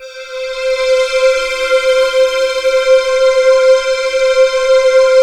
ALPS C5.wav